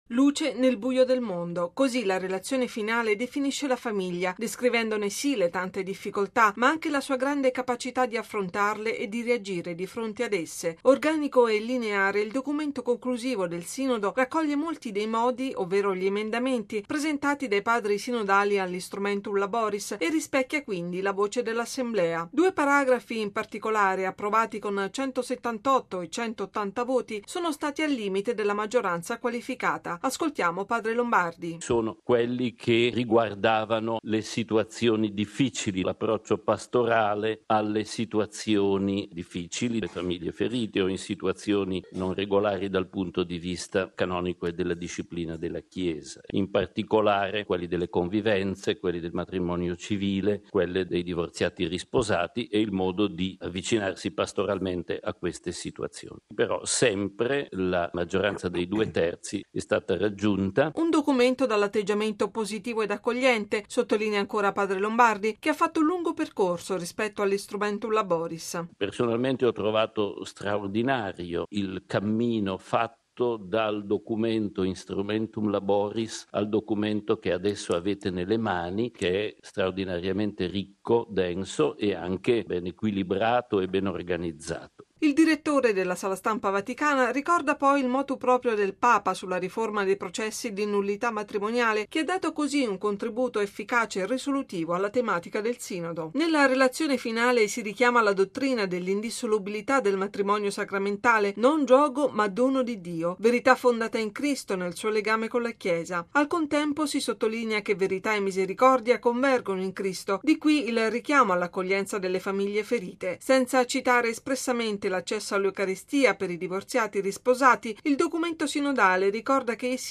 Bollettino Radiogiornale del 25/10/2015